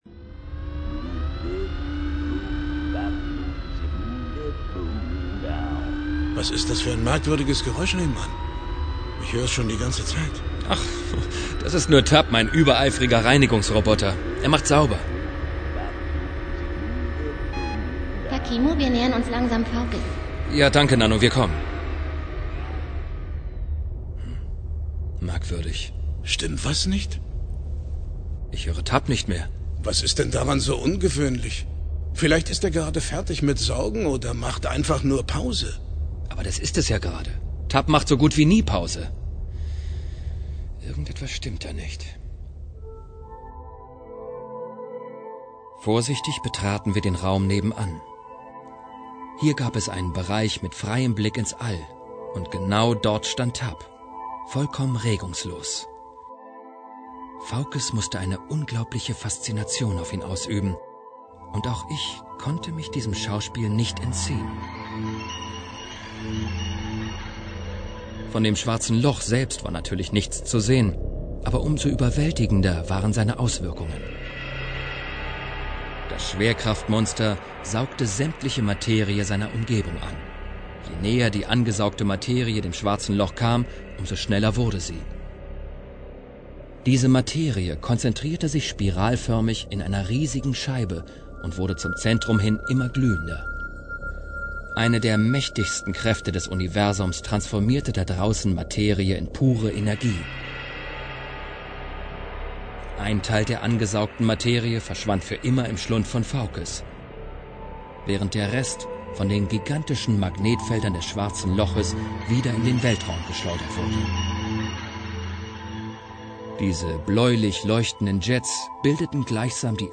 So macht das Gesamtprodukt nicht nur auf einer großen Stereoanlage einen perfekten Eindruck. Liebevolle Sprecherauswahl, interessante Toneffekte und eine sehr gute Musik lassen vor dem geistigen Auge die Handlung sehr plastisch auferstehen.
Hörprobe (mit freundlicher Genehmigung von Polaris Hörspiele, Berlin)